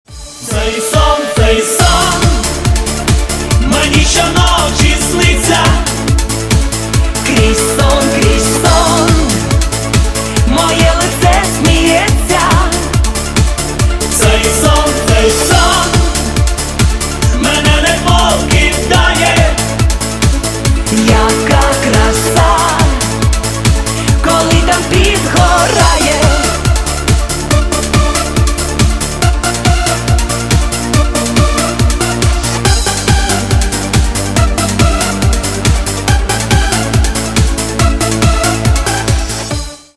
Танцевальные
ритмичные